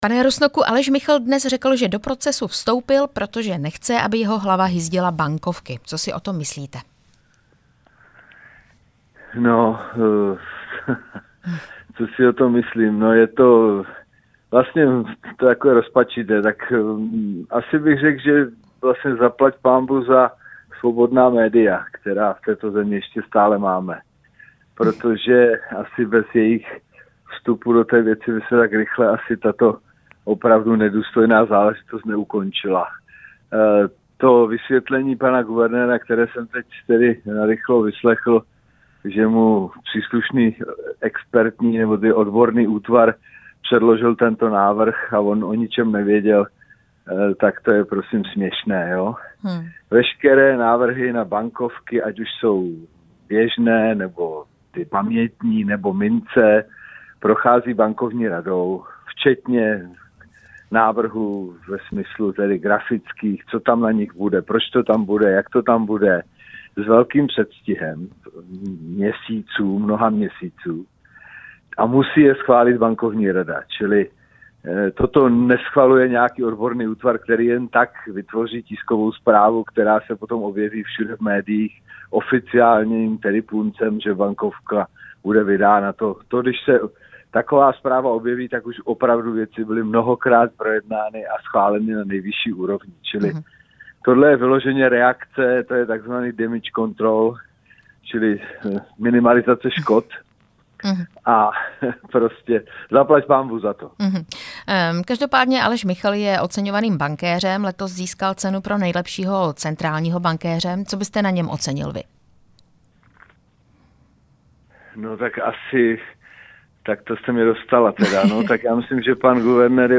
Rozhovor s bývalým guvernérem ČNB Jiřím Rusnokem